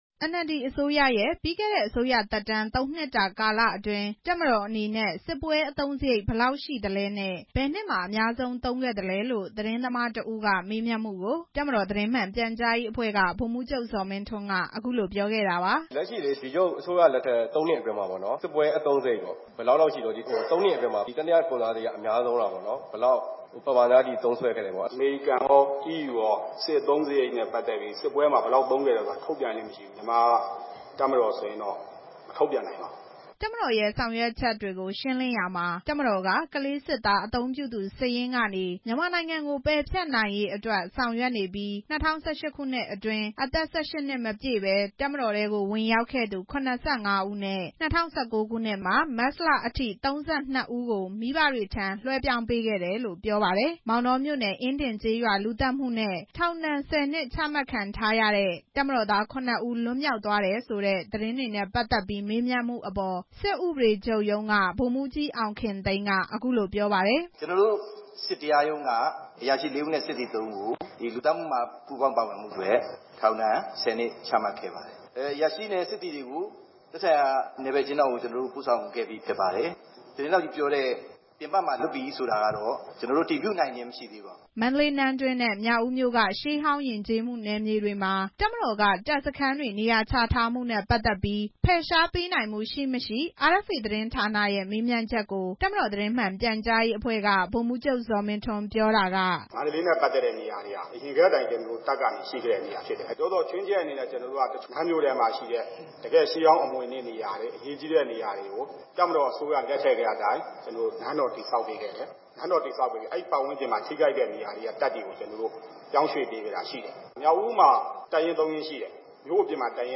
မြန်မာ့တပ်မတော်ရဲ့ စစ်ပွဲအသုံးစရိတ်တွေကို သတင်းထုတ်ပြန်ပေးဖို့မရှိဘူးလို့ တပ်မတော်သတင်းမှန်ပြန်ကြားရေးအဖွဲ့က ဗိုလ်မှူးချုပ် ဇော်မင်းထွန်းက ဒီနေ့ နေပြည်တော်မှာ ကျင်းပတဲ့ ကာကွယ်ရေးဝန်ကြီးဌာနရဲ့ သတင်းစာ ရှင်းလင်းပွဲမှာ ပြောပါတယ်။